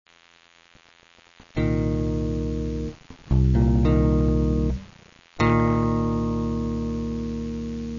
pour les accords mineur7 ou mineur7b5:
Si vous n'avez pas déjà capté..., en cliquant sur les tablatures, vous aurez l'illustration sonore (en C).
On utilise la main droite exactement de la même manière que sur une guitare acoustique: le pouce pour les notes graves, et l'index et majeur pour les notes aiguës sans buté mais simplement en pinçant.
accordmineur7-2.WAV